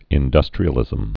(ĭn-dŭstrē-ə-lĭzəm)